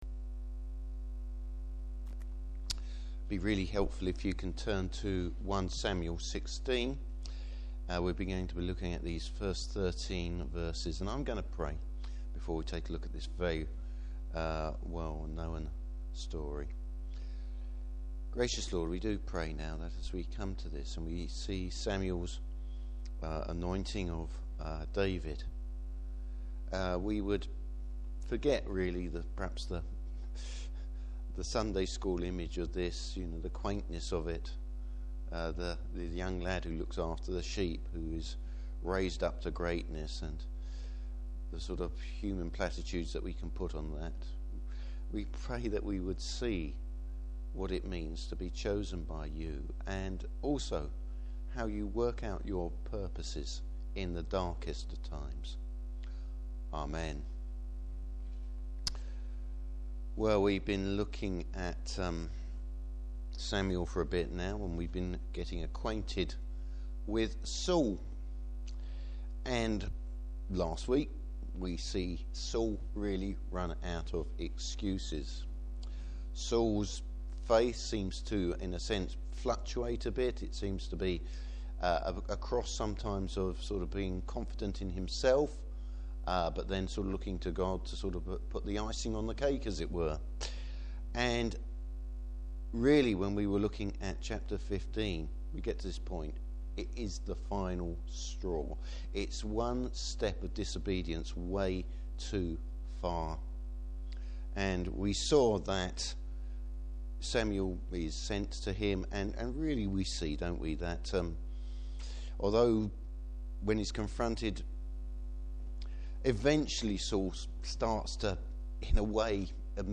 Service Type: Evening Service What's the Lord's agenda when selecting a leader for his people?